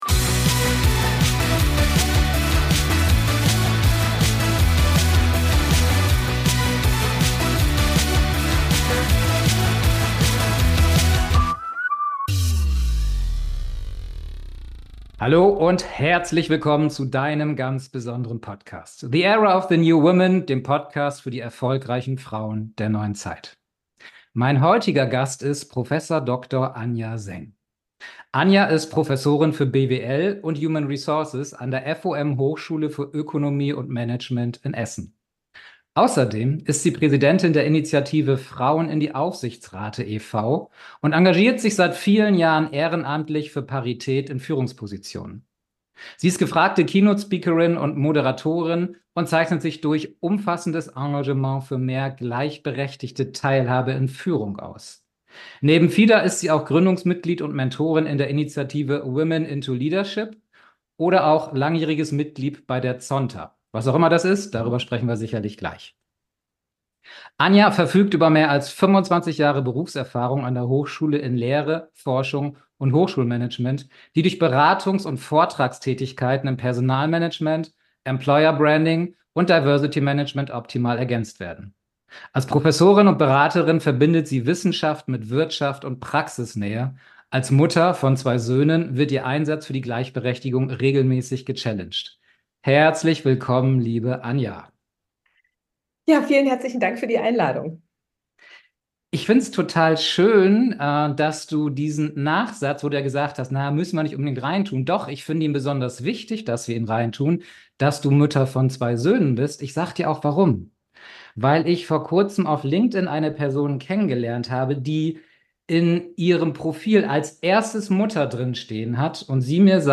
#016 Wie sehr ist Gleichberechtigung wirklich möglich? Interview